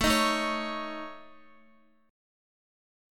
AMb5 chord